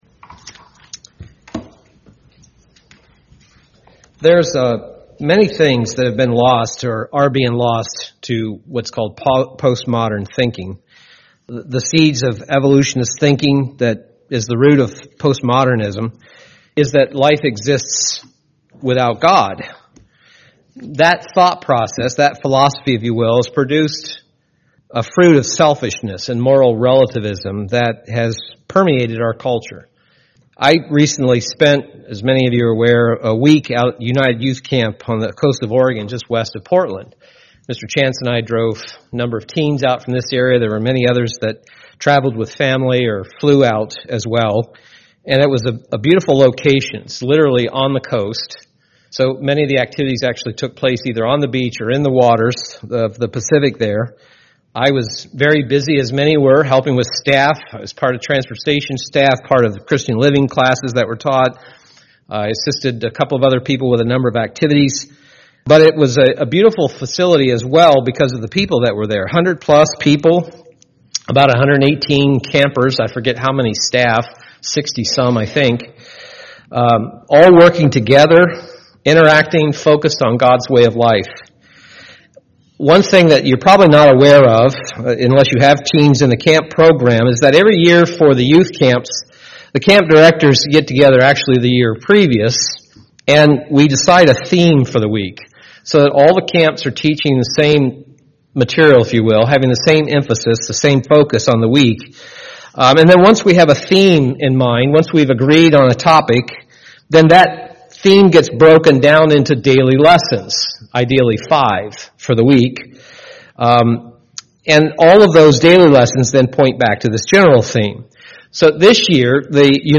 Print Aspect of Godly character from the 2014 UYC theme UCG Sermon Studying the bible?